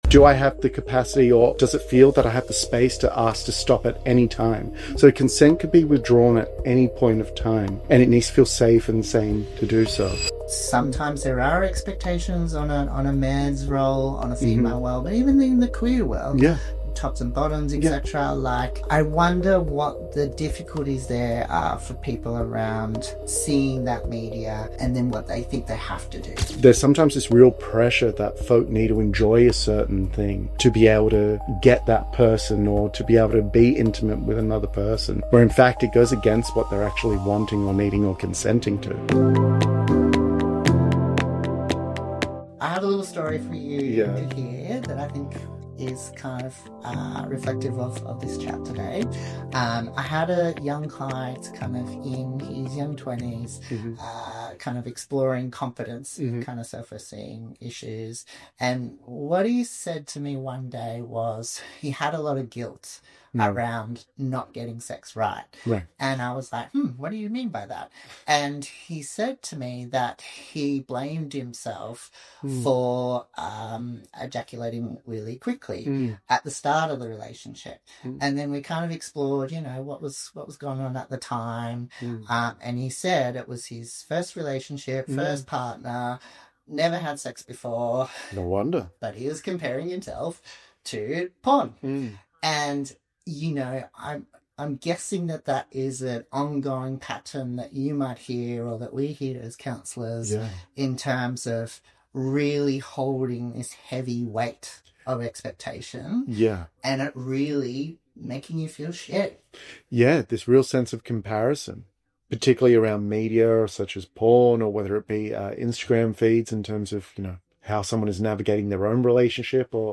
An Honest Talk Safe Place Therapy Sexologist